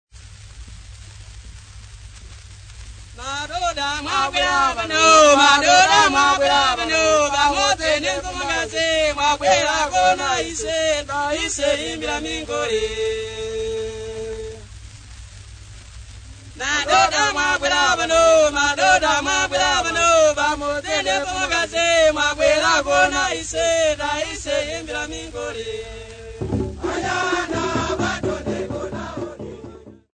Nyanja Men
Folk Music
Field recordings
Africa Malawi City not specified f-mw
sound recording-musical
Indigenous music
96000Hz 24Bit Stereo